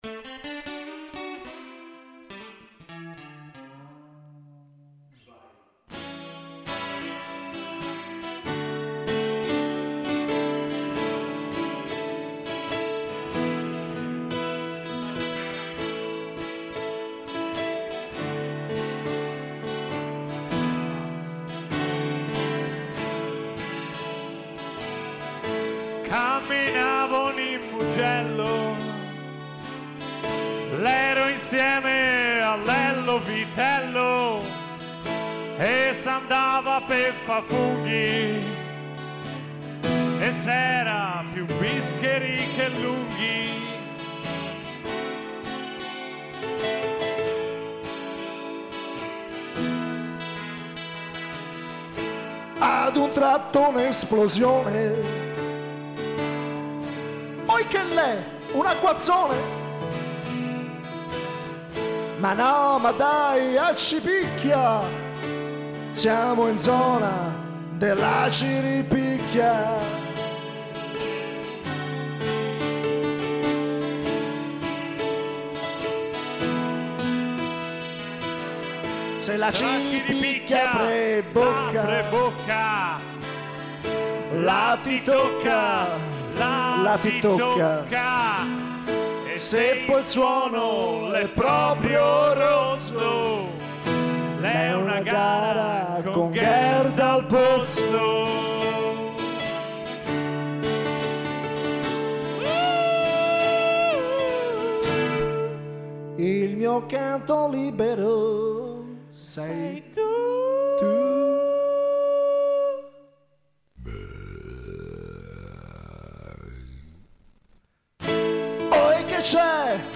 Qui ne canto le gesta insieme ad un caro amico anche lui frequentatore dello stesso newsgroup